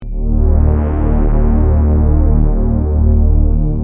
Free MP3 vintage Sequential circuits Pro-600 loops & sound effects 2